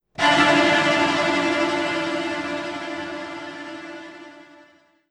effect__stinger_2.wav